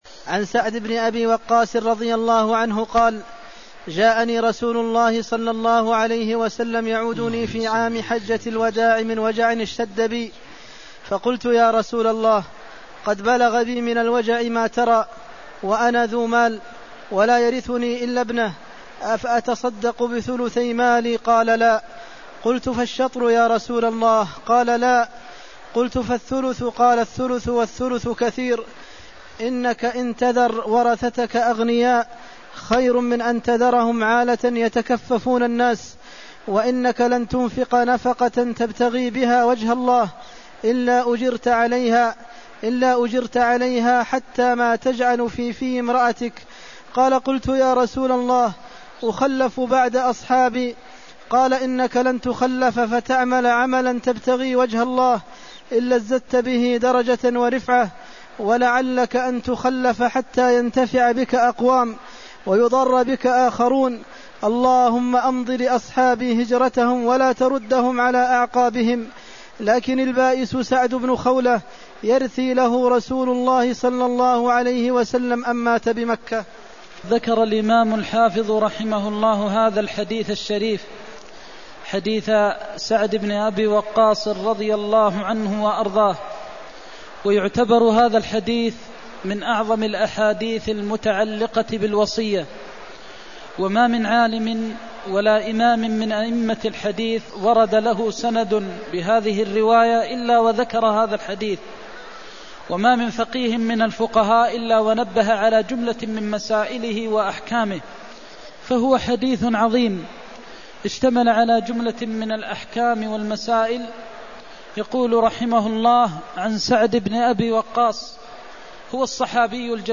المكان: المسجد النبوي الشيخ: فضيلة الشيخ د. محمد بن محمد المختار فضيلة الشيخ د. محمد بن محمد المختار الثلث والثلث كثير (279)